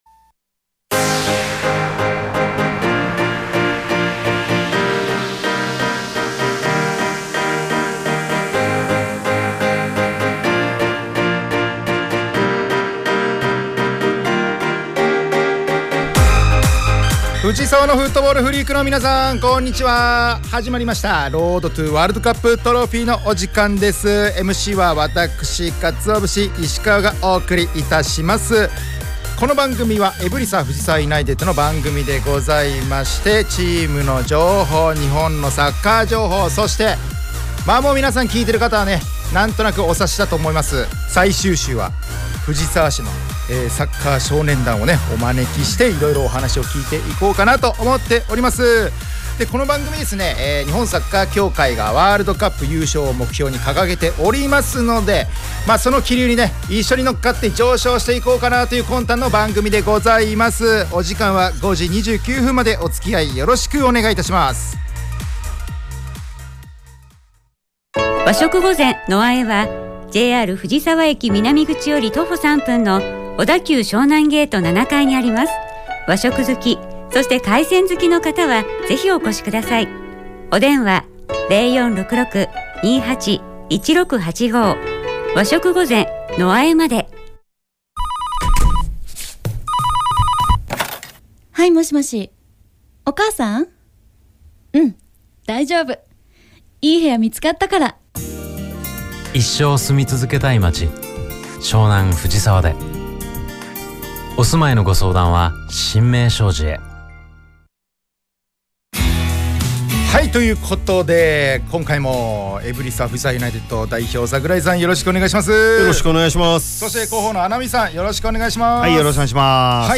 エブリサ藤沢ユナイテッドが提供する藤沢サッカー専門ラジオ番組『Road to WC Trophy』の第2期の第99回放送が2月27日(金)17時に行われました☆